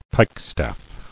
[pike·staff]